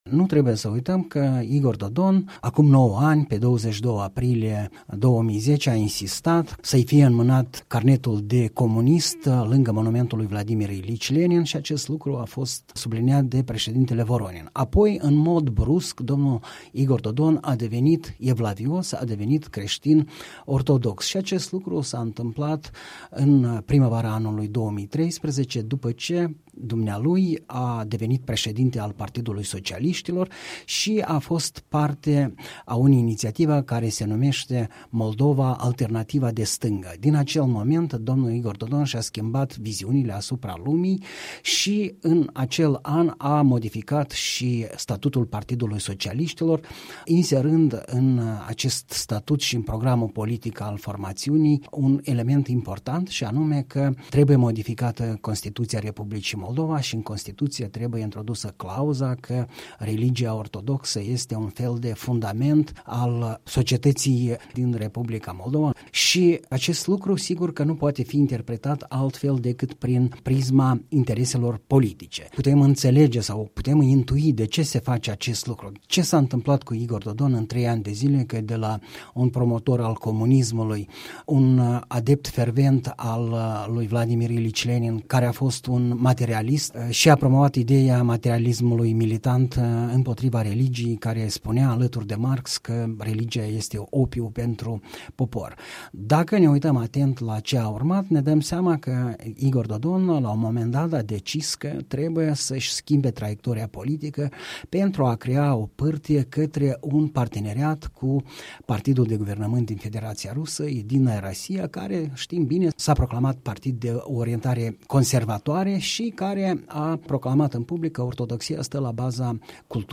Comentariu săptămânal, în dialog la Europa Liberă.